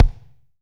B.B KICK 4.wav